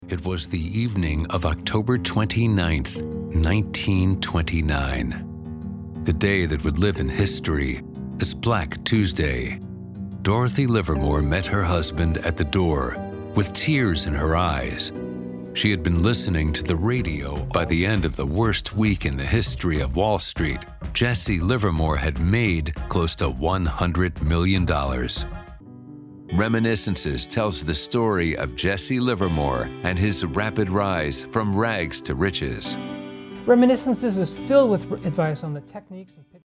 English (American)
TV Shows
Full-time working voice actor with professional home studio for pristine sound.
Sennheiser 416 mic